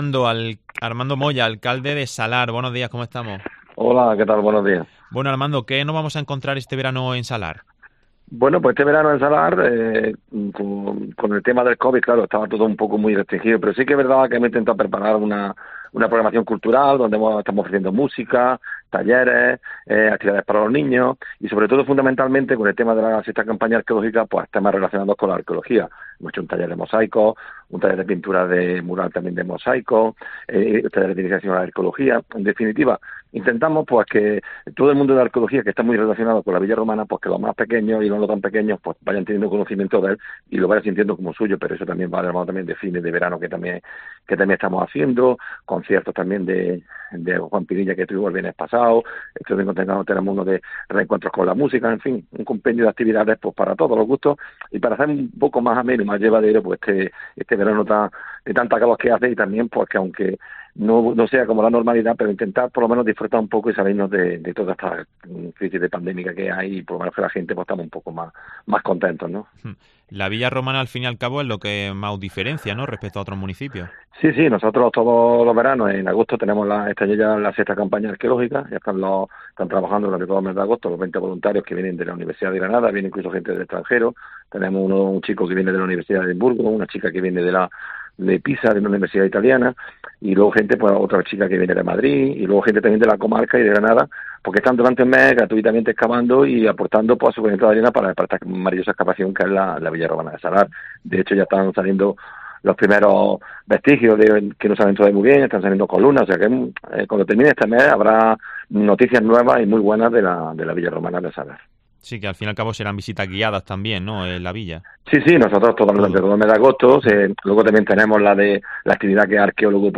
En COPE, hemos hablado con su alcalde, Armando Moya.